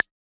RDM_Raw_MT40-Clave.wav